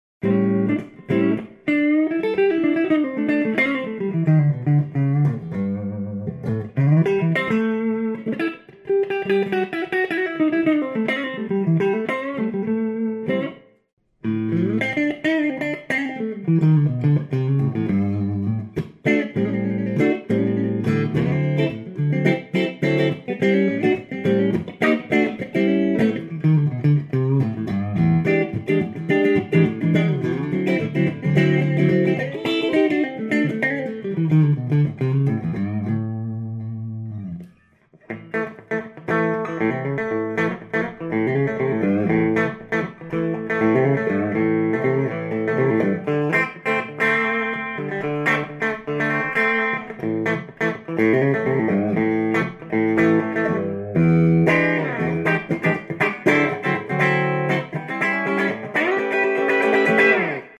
Neck voiced for Jazz/Clean, Bridge voiced for Rock and Blues.